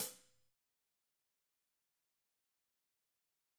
TC2 Live Hihat17.wav